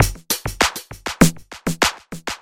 Descarga de Sonidos mp3 Gratis: sintetizador 6.
descargar sonido mp3 sintetizador 6